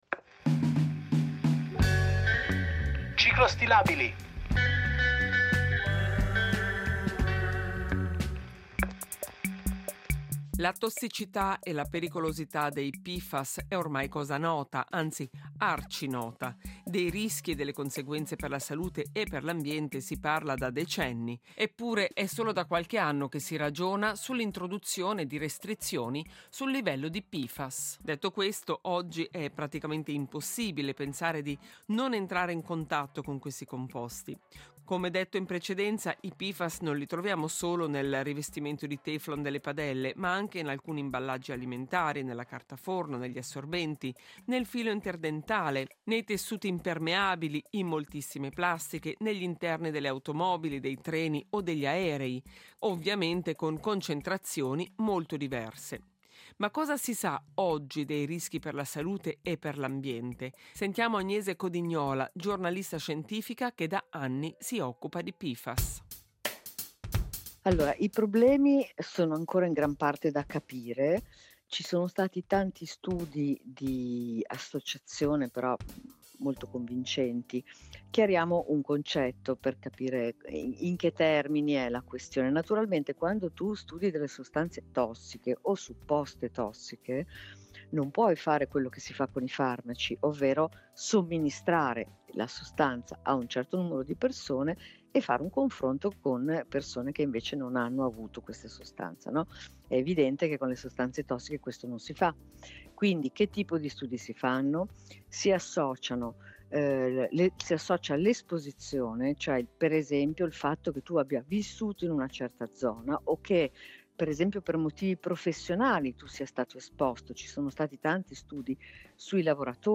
ha intervistato la giornalista scientifica